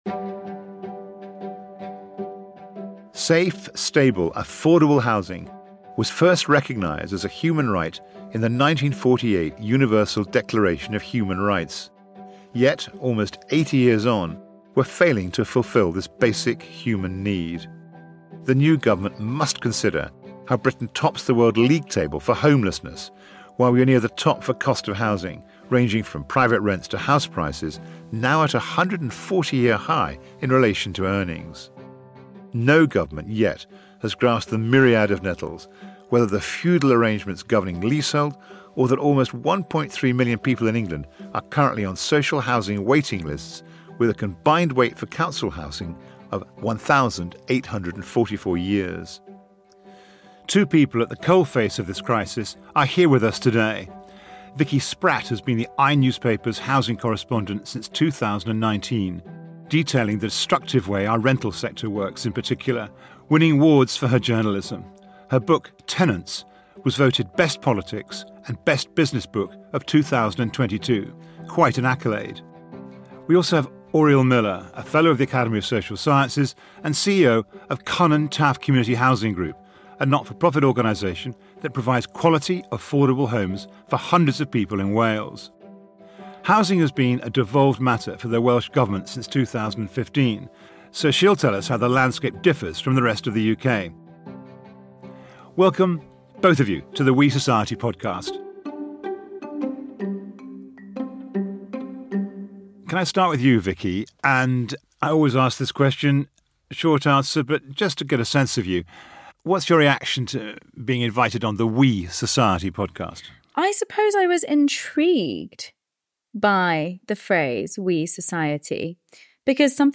Hosted by journalist and Academy President Will Hutton, we interview some of Britain’s top social scientists and public figures from across the globe to explore their evidence-led solutions to society’s most pressing problems.